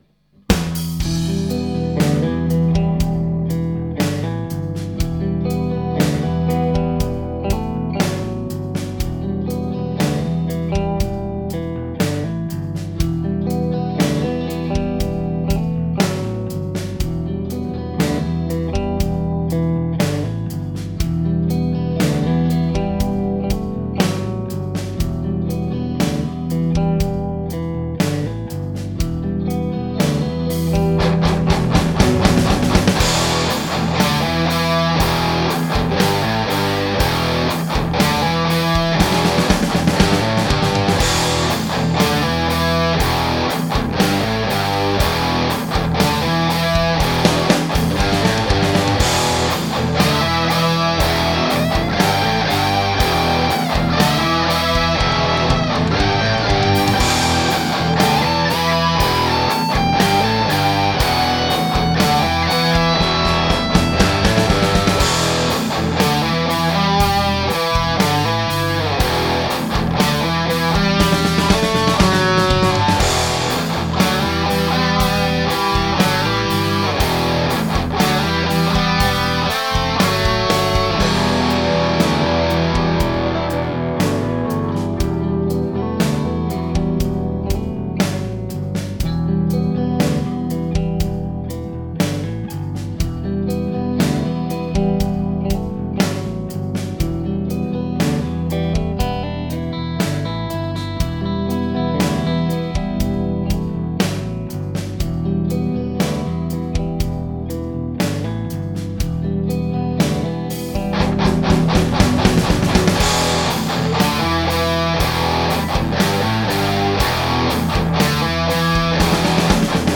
I also added a slight bit of gating on the interlude 'cos it was getting a bit out of control there's also a little wah wah lick after the interlude and I decided I wanted it down the bottom of a well.